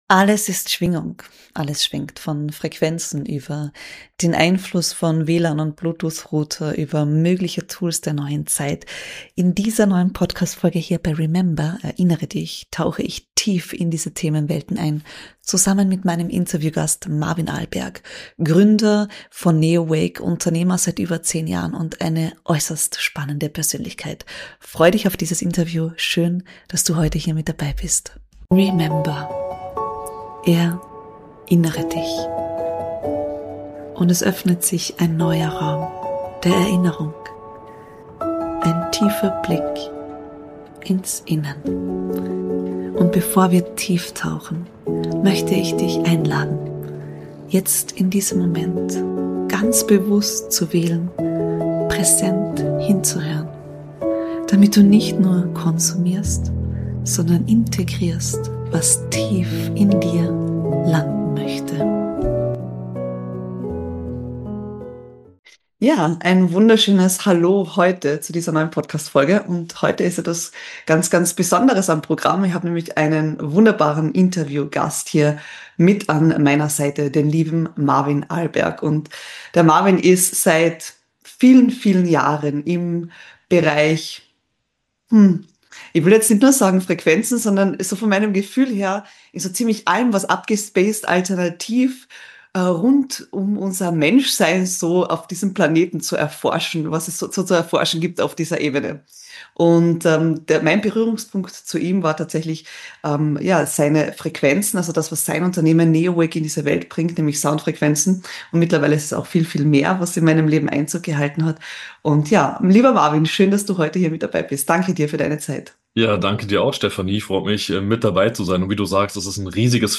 #96 - Im Gespräch